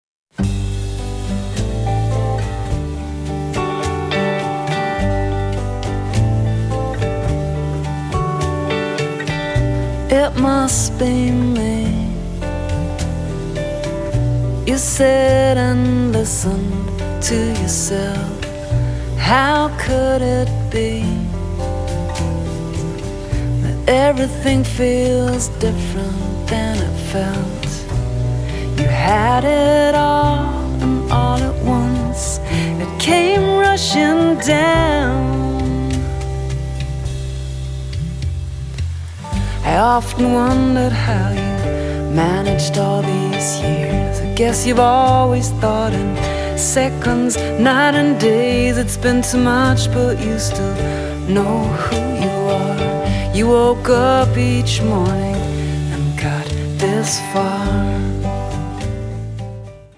vocals, acoustic guitar
acoustic and electric guitars, wurlitzer
trumpet, fluegelhorn
drums, percussion